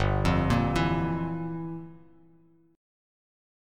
Gm13 Chord
Listen to Gm13 strummed